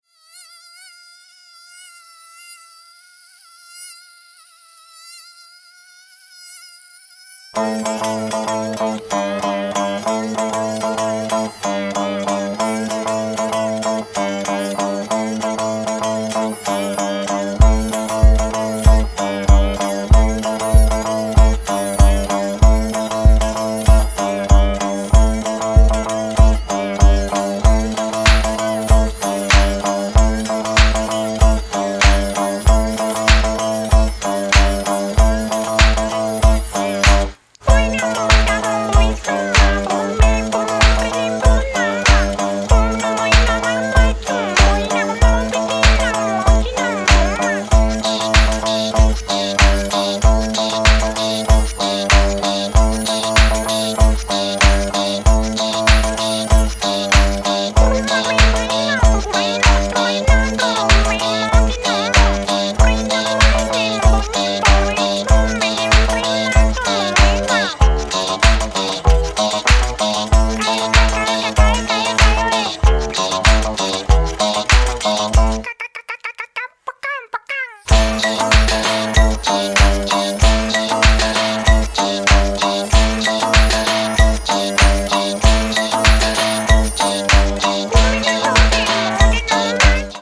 子供向け音楽を想起させるような面白さがあり◎！